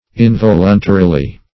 Involuntarily \In*vol"un*ta*ri*ly\, adv. [From Involuntary.]